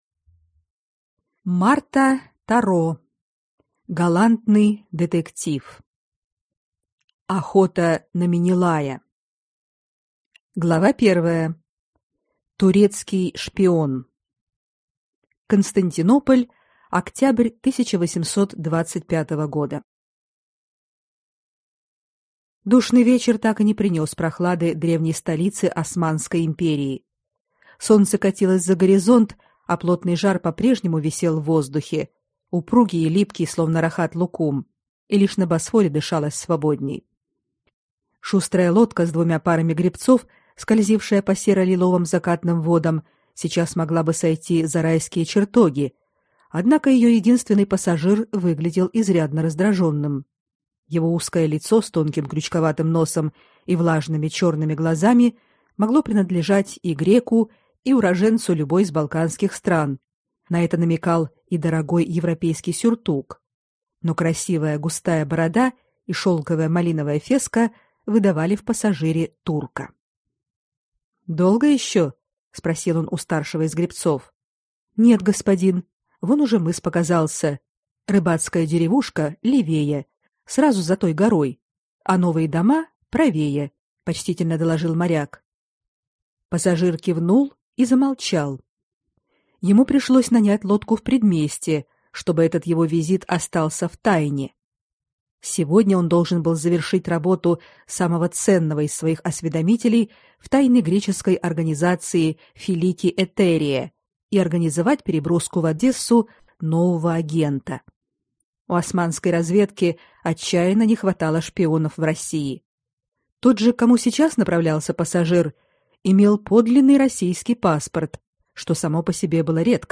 Аудиокнига Охота на Менелая | Библиотека аудиокниг